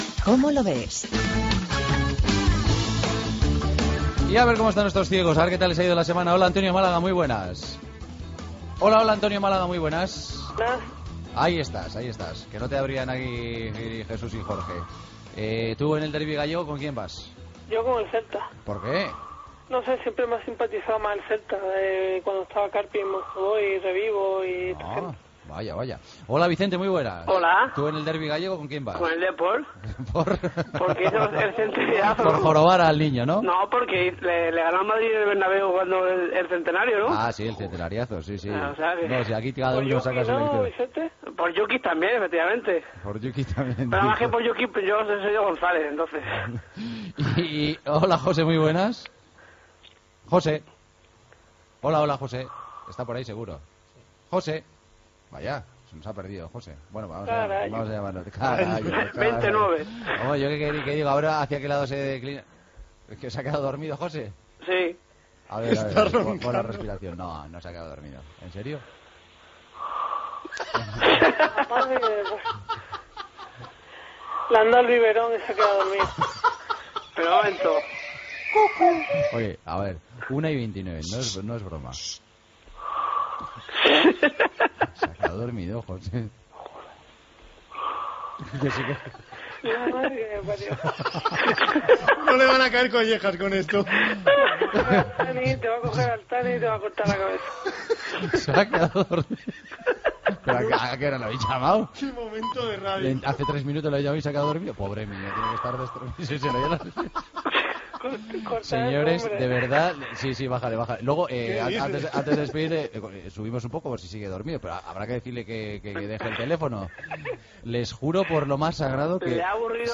Secció "Como lo ves", dóna pas a tres invitats per parlar del partit Celta Deportivo de la Coruña. Un dels tres col·laboradors s'ha quedat adormit al telèfon
Esportiu
FM